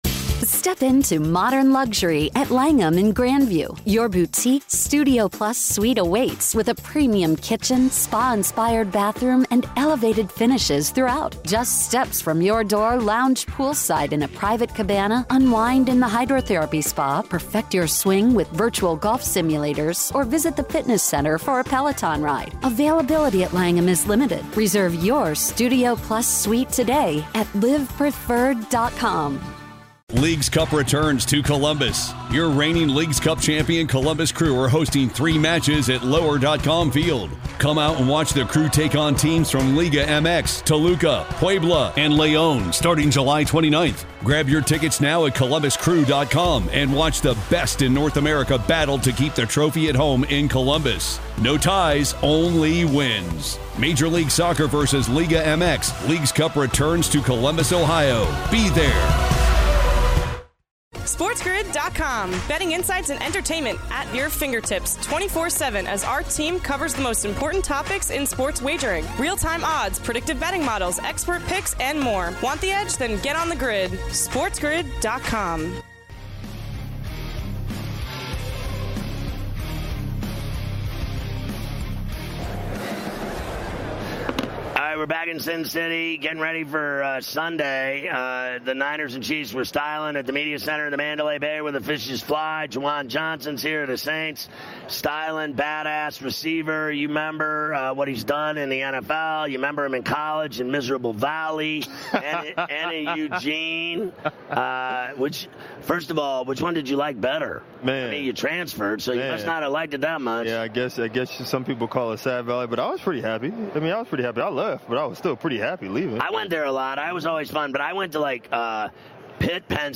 On this episode, Ferrall welcomes the biggest guests including Juwan Johnson and Claressa Shields live from Radio Row in Las Vegas as he prepares for Super Bowl LVIII!
Legendary sports shock jock Scott Ferrall takes the gaming world by storm with his “in your face” style, previewing the evening slate of games going over lines, totals and props, keeping you out of harms way and on the right side of the line.